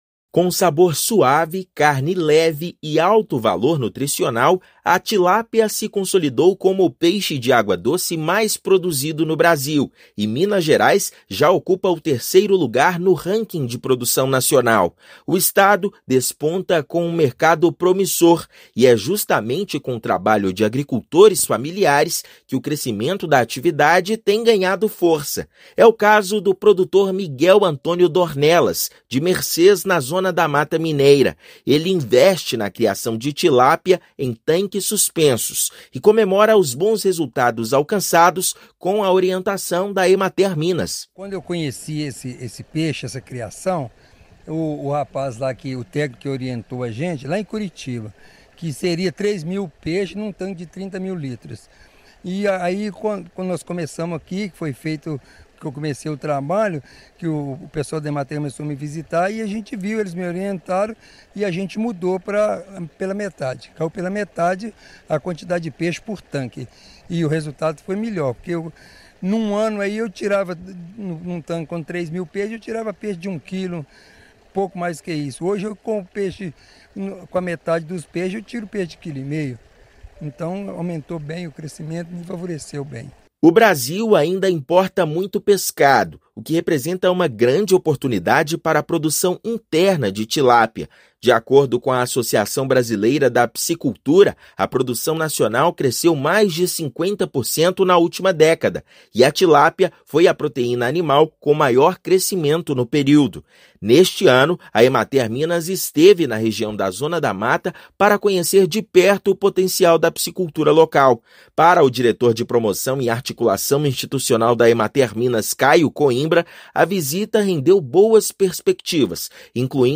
[RÁDIO] Minas Gerais é o terceiro maior produtor nacional de tilápia
A atividade ganha força no estado entre os agricultores familiares e o peixe ganha espaço na alimentação escolar. Ouça matéria de rádio.